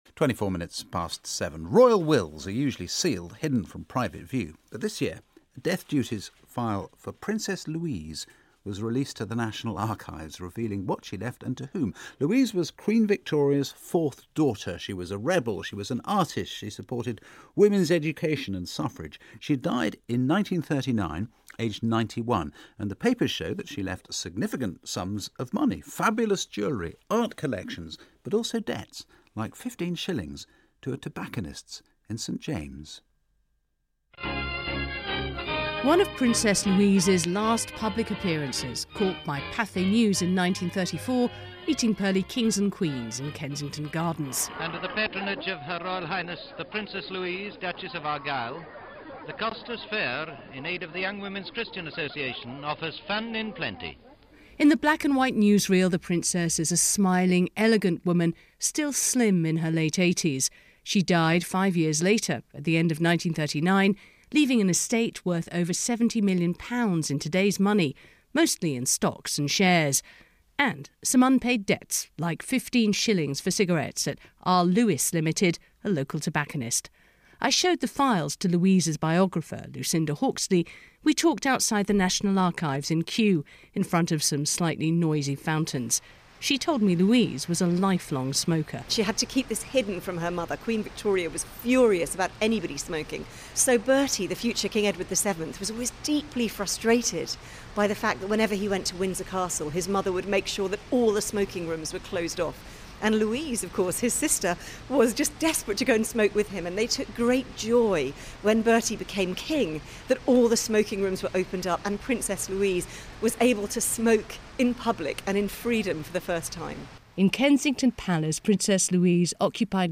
Queen Victoria's rebel daughter left unpaid debts - including one for cigarettes - and a fabulous jewellery and art collection. My radio package here.